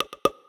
Special Pop (1).wav